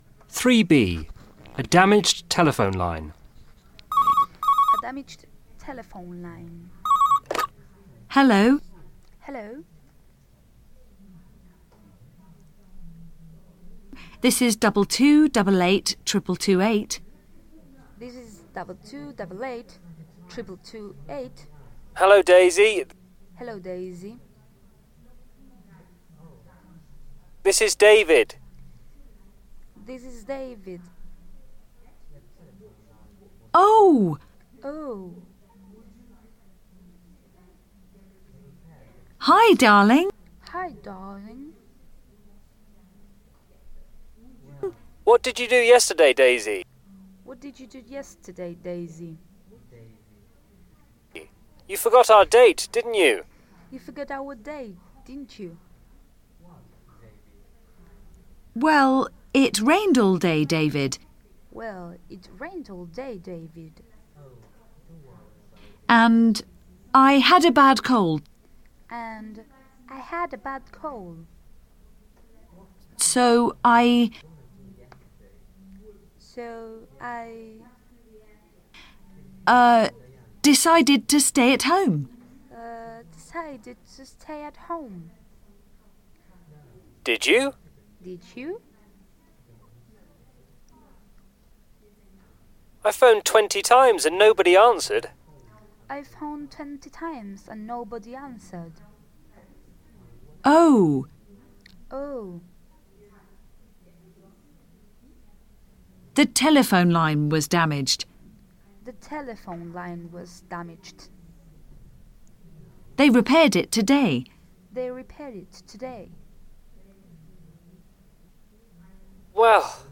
a damaged telephone line